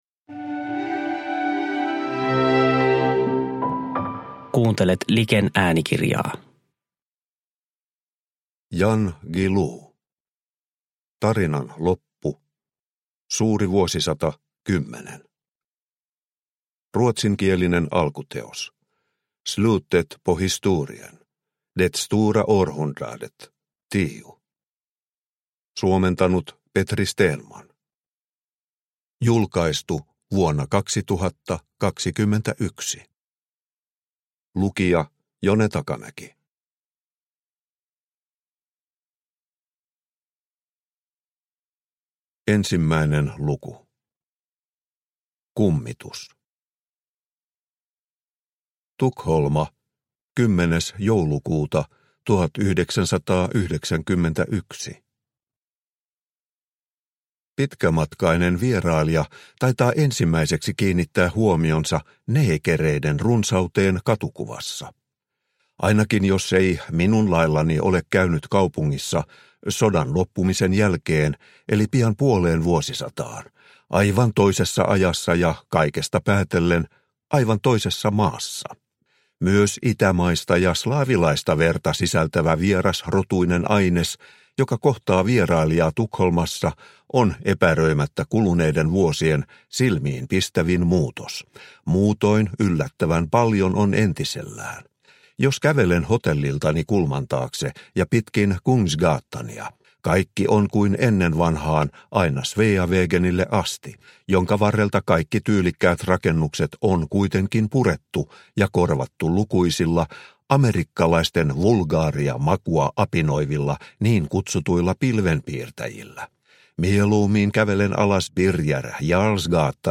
Tarinan loppu – Ljudbok – Laddas ner